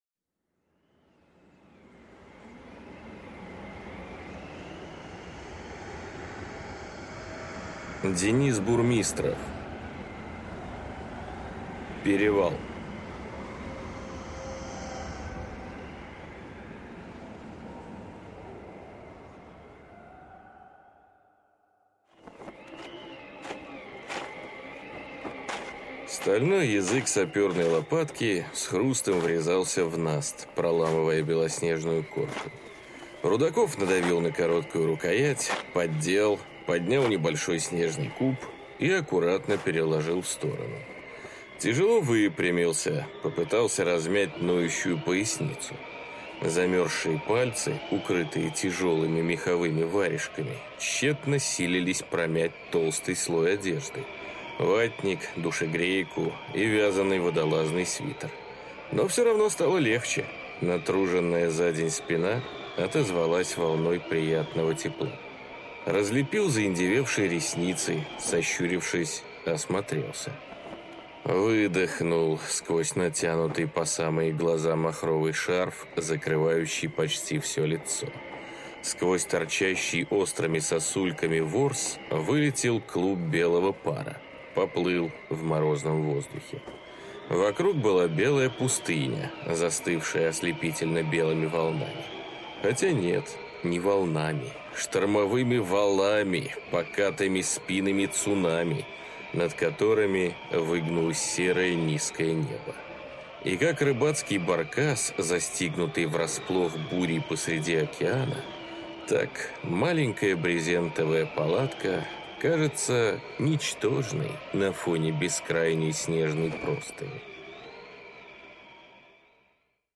Аудиокнига Перевал | Библиотека аудиокниг